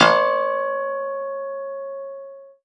53x-pno04-C3.wav